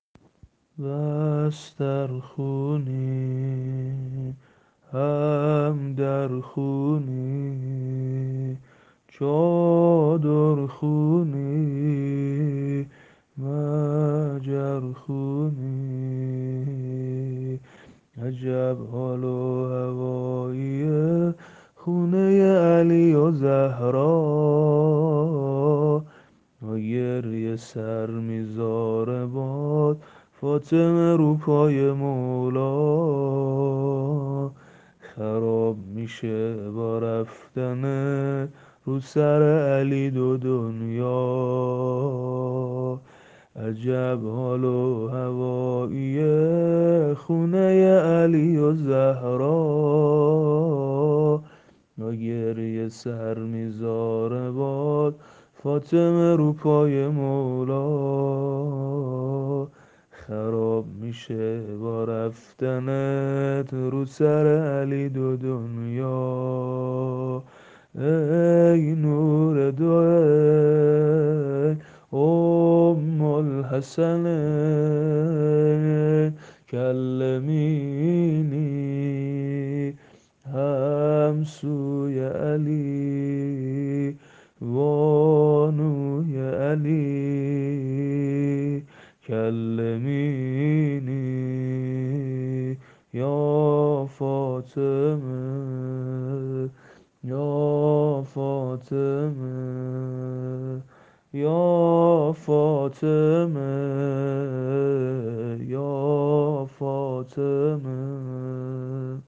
زمینه شهادت حضرت زهرا(س)
عنوان : سبک زمینه شهادت حضرت زهرا 96